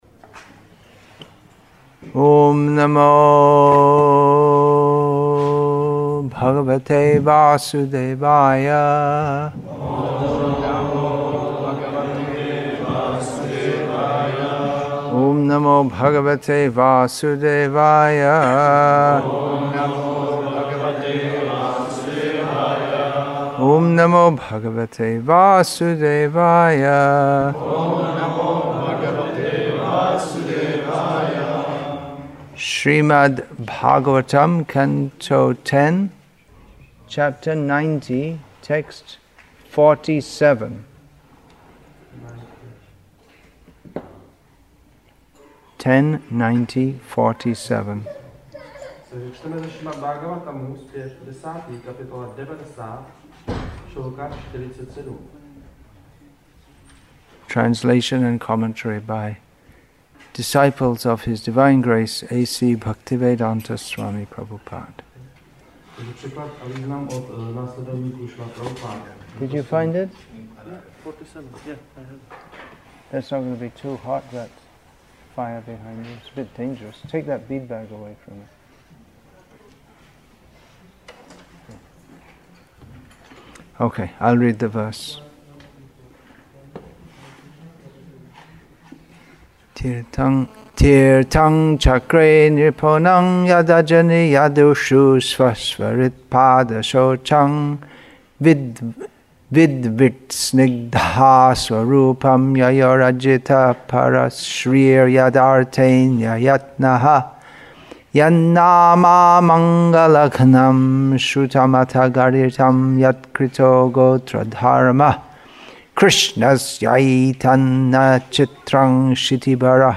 Krsna fulfills all Our Desires September 3, 2018 Śrīmad-Bhāgavatam English with Český (Czech) Translation; Nava Gokula FarmCzech Republic , Śrīmad-Bhāgavatam 10.90.47 50 m 48.49 MB Download Play Add To Playlist Download